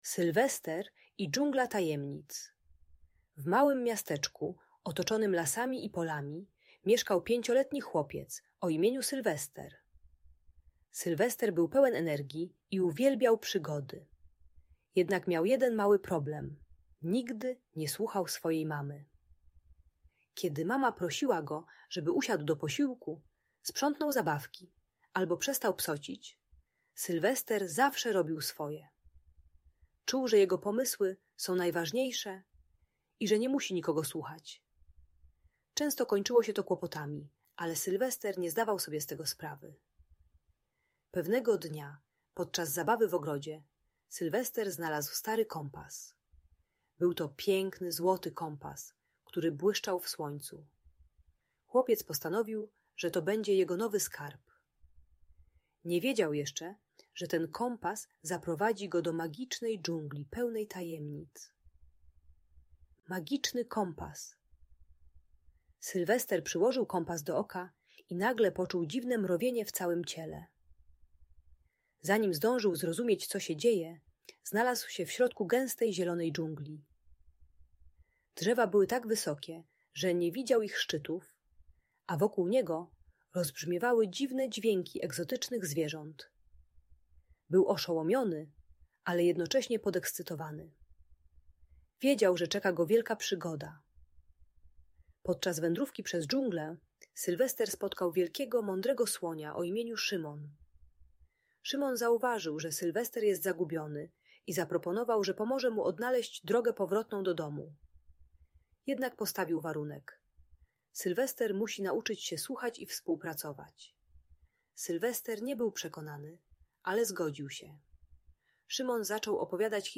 Sylwester i Dżungla Tajemnic - Agresja do rodziców | Audiobajka